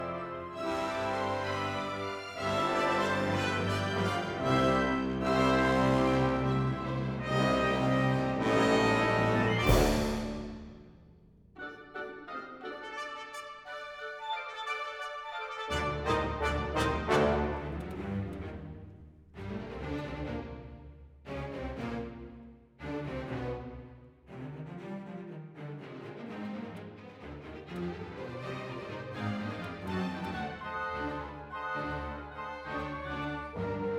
мастер - то, что пошло в колонки после прикидки по влажности, остальные - соответственно Пойду я подрыхну, завтра может еще похимичу...